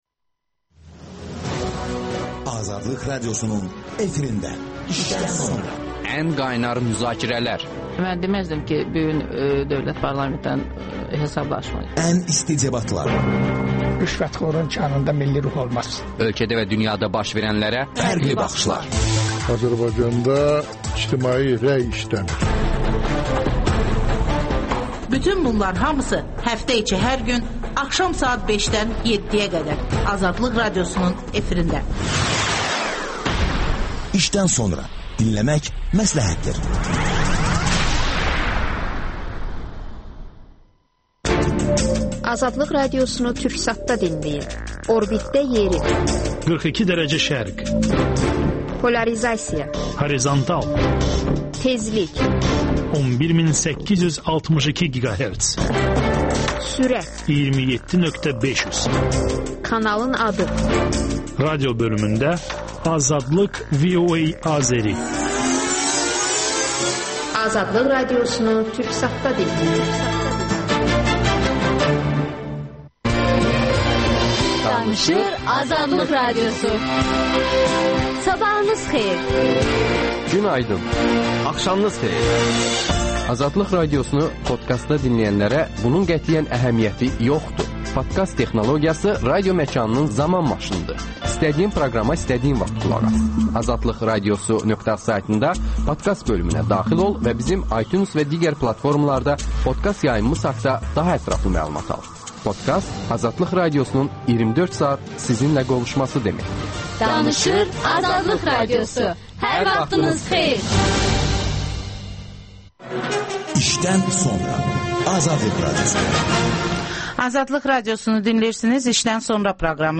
söhbət